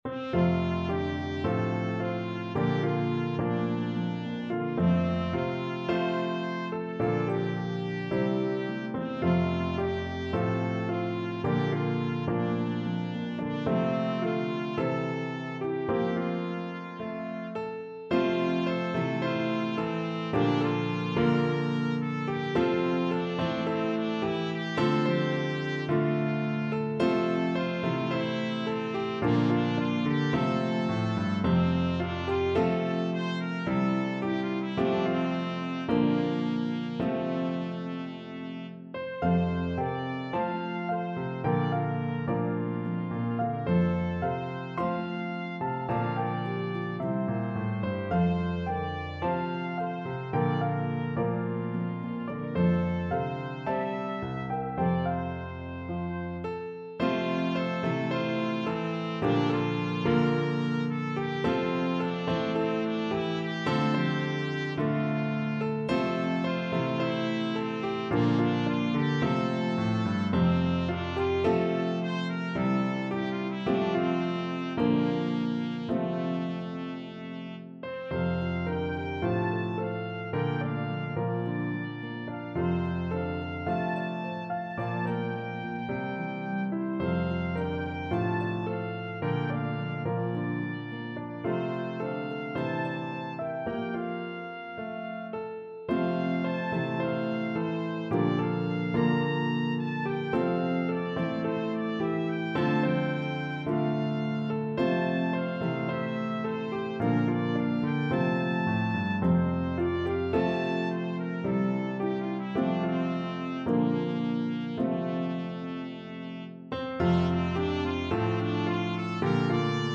pedal harp
Harp, Piano, and Trumpet in B-flat version